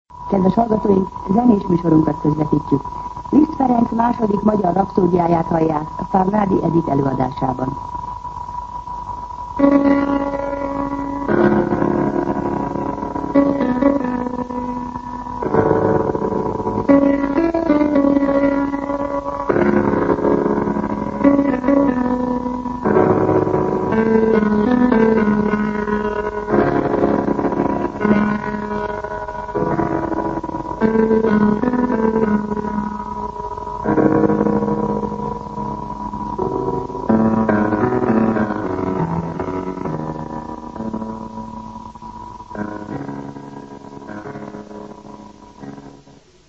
Zene
Zenés műsorunkat közvetítjük.